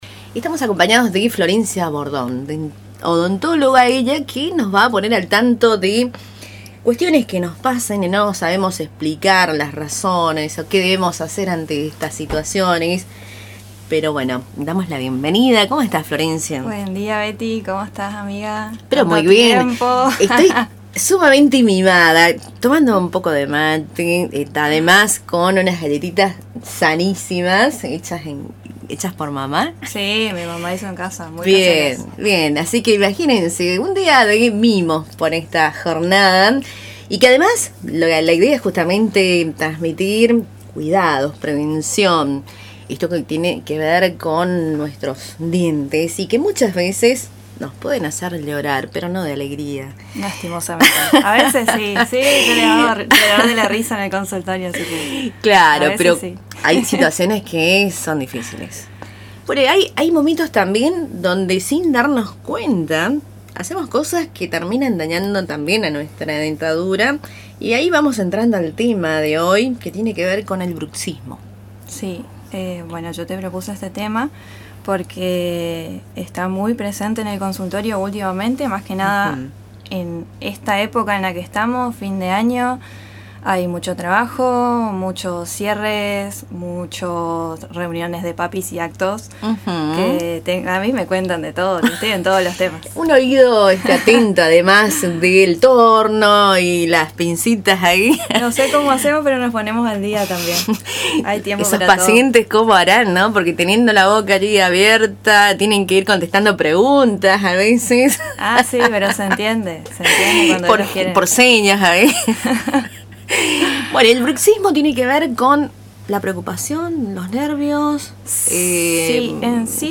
charla sobre salud bucal